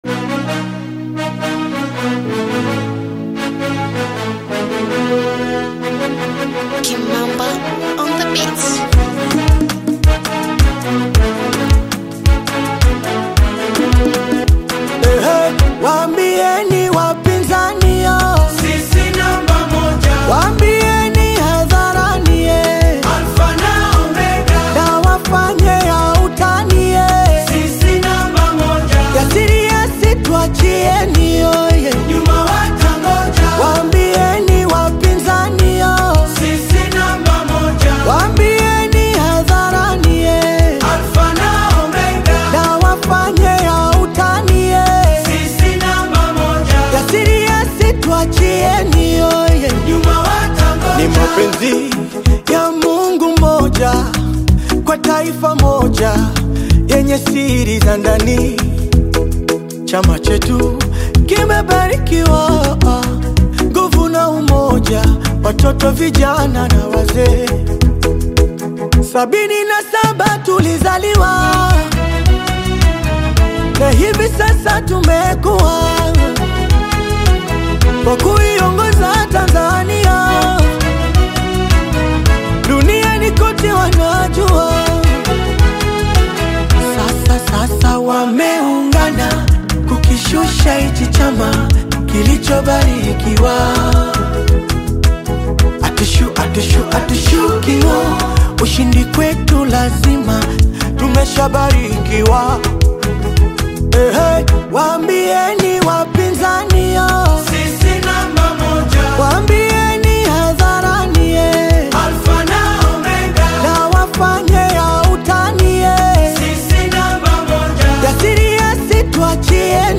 new rap single